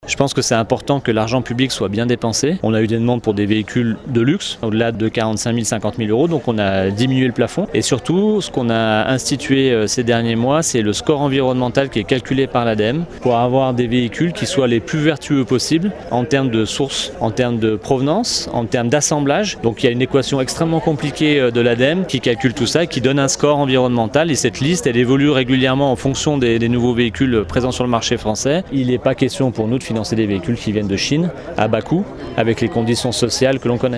Plusieurs critères d’éligibilité ont été actés pour éviter les abus comme l’explique Raphaël Castera, le maire de Passy et Vice-président de la Communauté de communes Pays du Mont-Blanc, en charge de l’environnement :